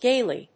音節gay・ly 発音記号・読み方
/géɪli(米国英語)/